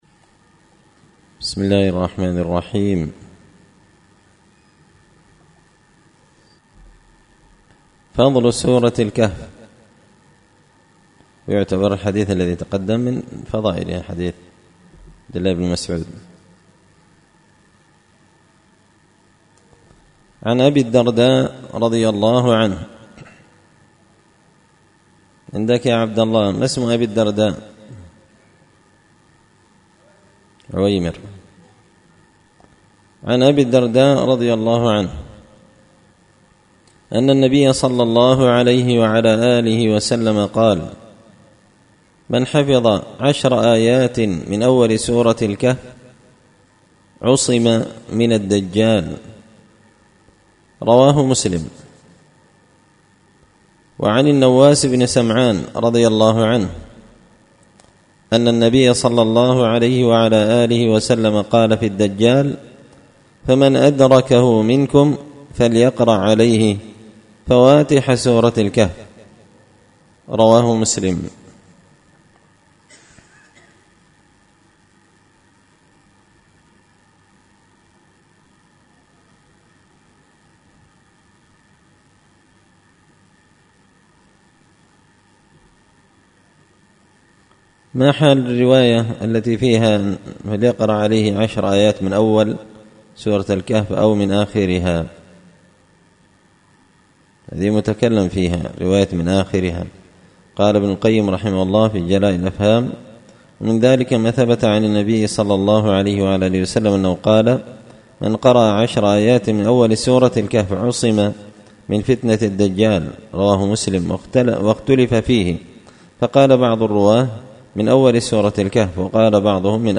الأحاديث الحسان فيما صح من فضائل سور القرآن ـ الدرس التاسع والعشرون